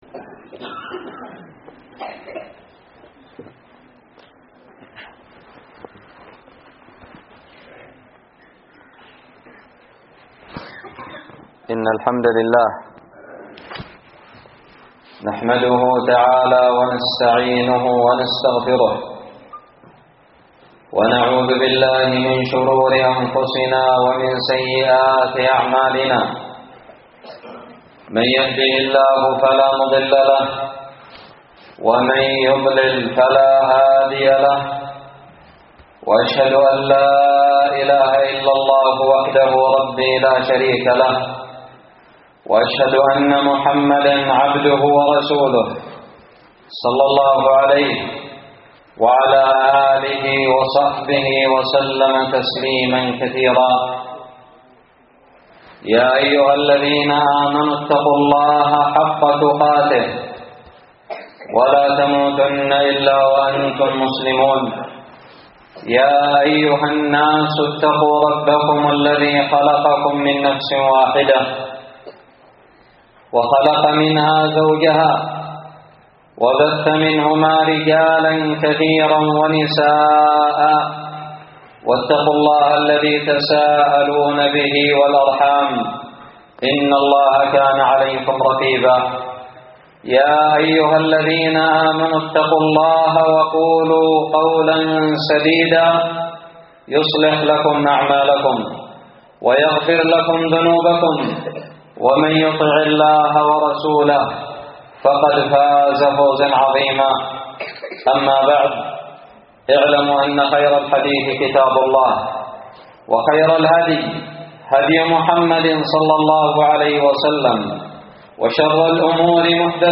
خطب الجمعة
ألقيت بدار الحديث السلفية للعلوم الشرعية بالضالع في 14 جمادى الآخرة 1439هــ